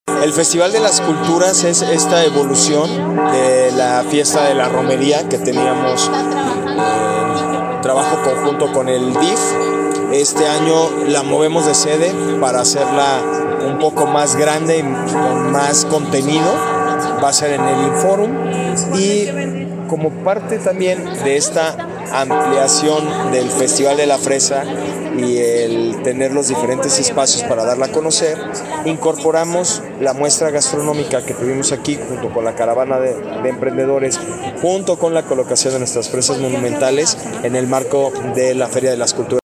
AudioBoletines
Héctor Muñoz, director de economía